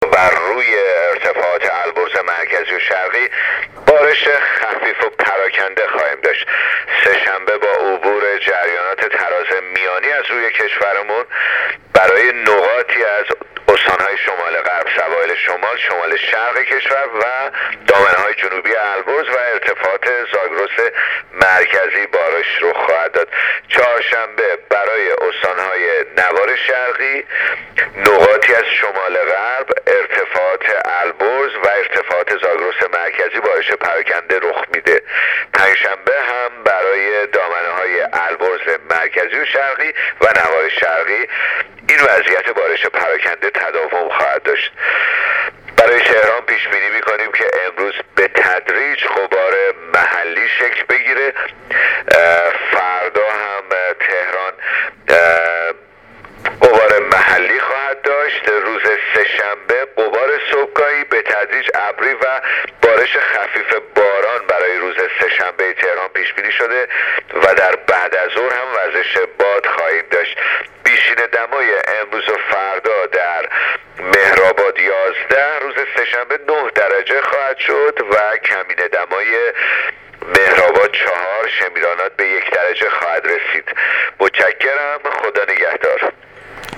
گزارش رادیو اینترنتی از آخرین وضعیت آب و هوای هشتم دی ۱۳۹۸